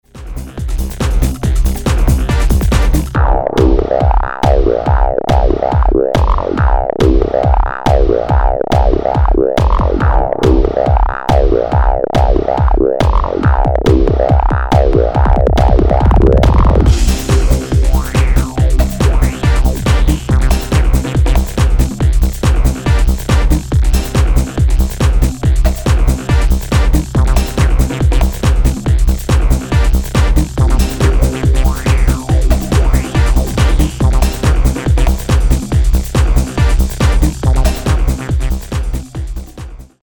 die songs 8 bis 13 sind techno-tracks (140 bpm)